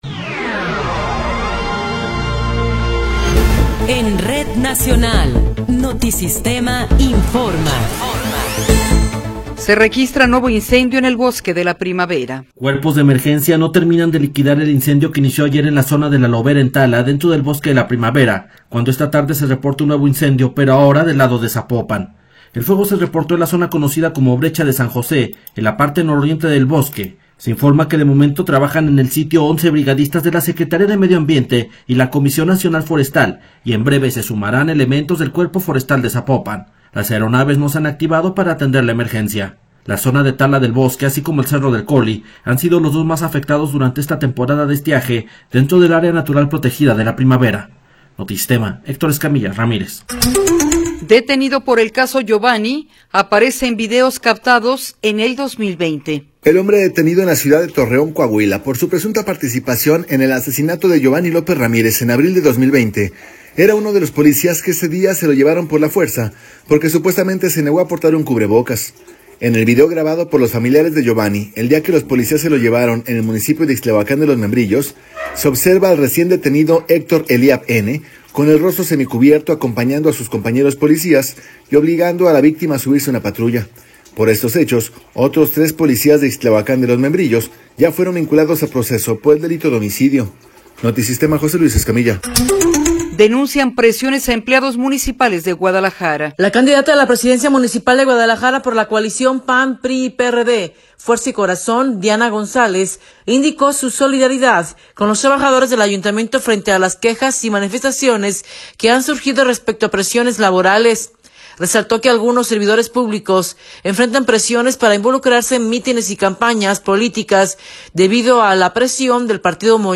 Noticiero 17 hrs. – 11 de Abril de 2024